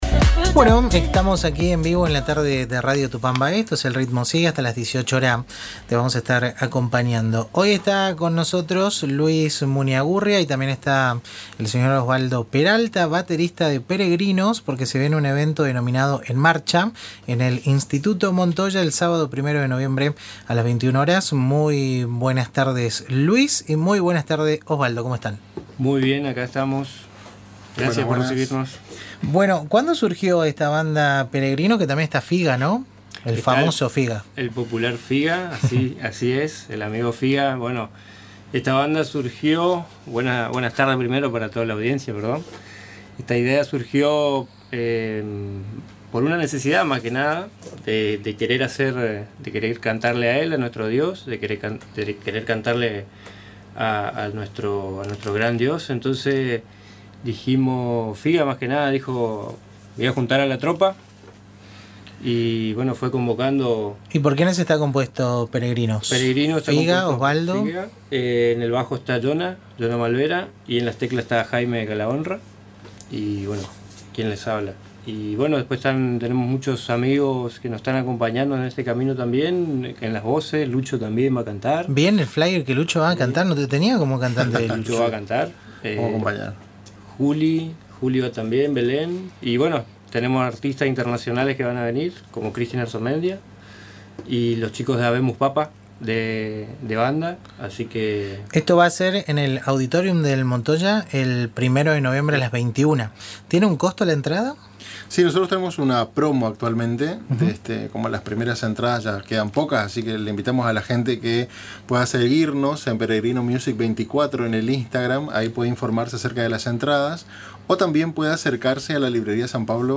En diálogo con El Ritmo Sigue, por Radio Tupambaé